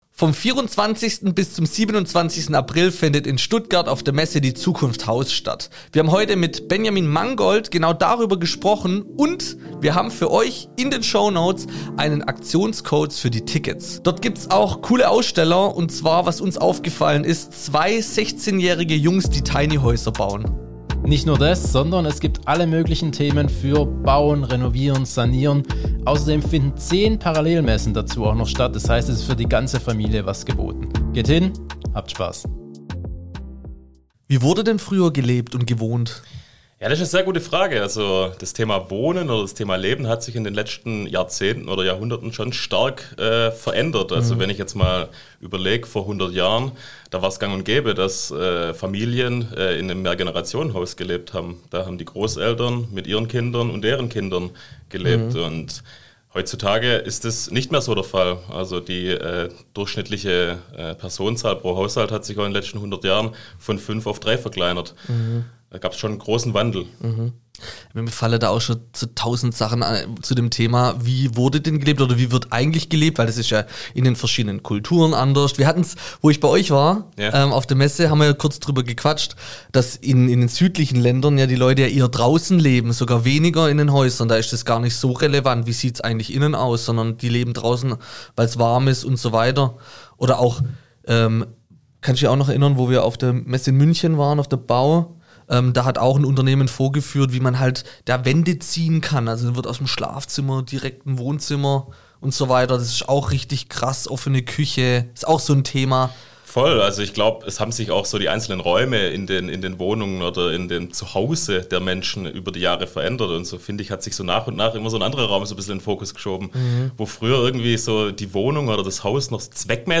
Sanierungsschock! Wer kann sich das Bauen leisten? Im Gespräch mit Prinz Pi & Tobi’s Tool Time 11 Mar · Bau Podcast - der Höfliche & der BAUstein Lytte senere Lytte senere Merk som spilt Vurder Nedlastning Gå til podcast Dele